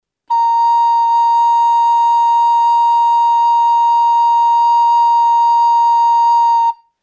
A nota Sib (Sibemol) ou La# (La sostenido).
A nota Sib (La#).